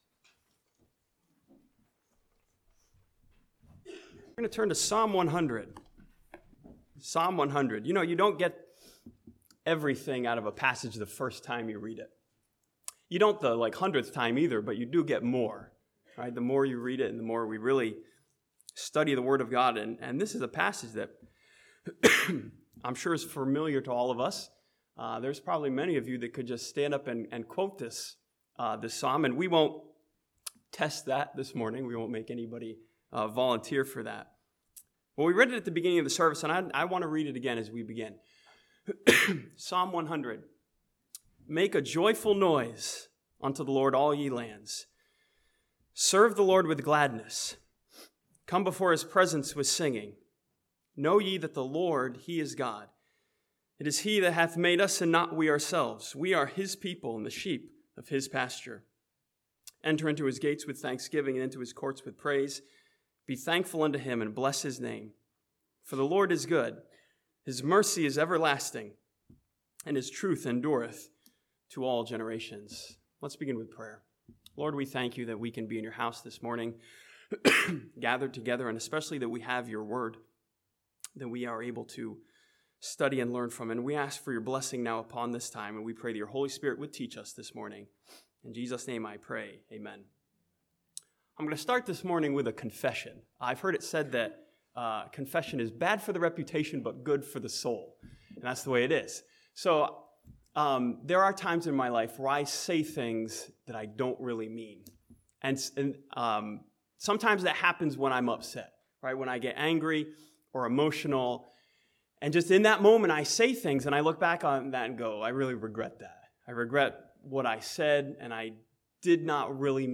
This sermon from Psalm 100 challenges believers to have an organic, heart-felt thankfulness this year, grown from thoughtfulness.